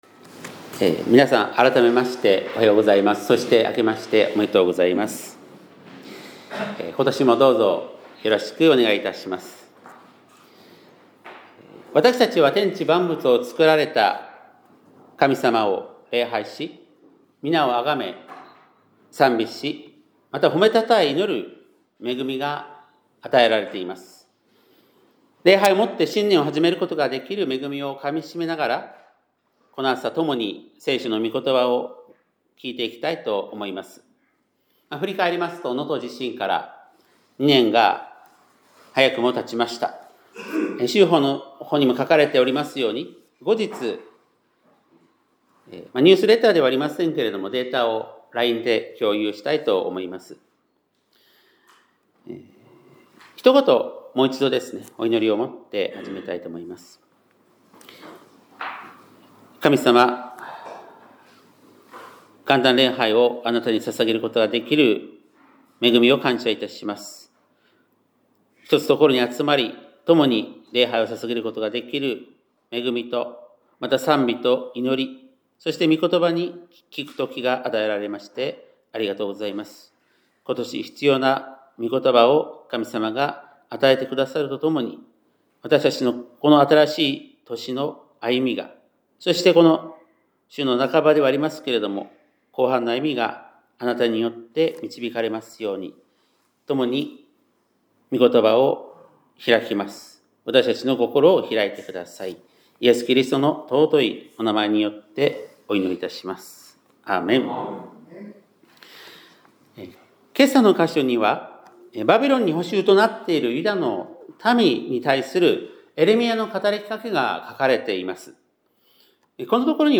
2026年1月1日（木）元旦礼拝メッセージ - 香川県高松市のキリスト教会
2026年1月1日（木）元旦礼拝メッセージ